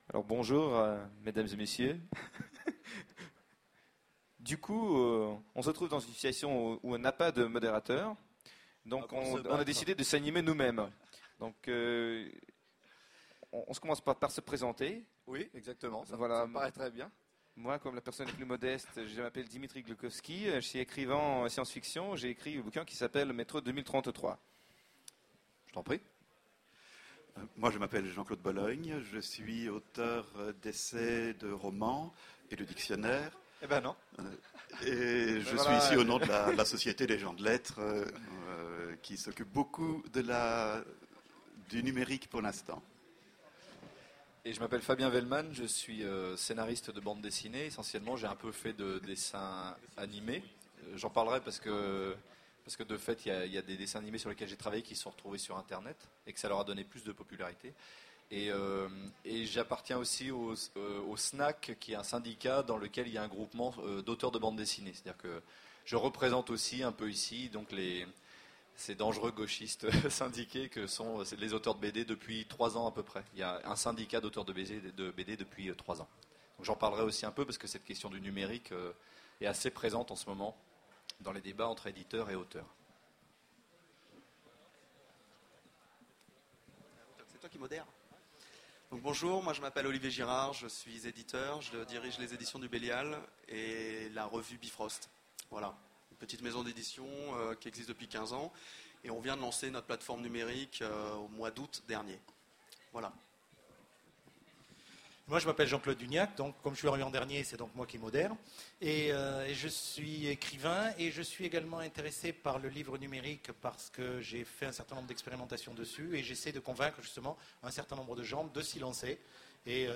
Voici l'enregistrement de la conférence " Du papier à l'écran " aux Utopiales 2010. Le livre numérique détrônera-t-il le papier ?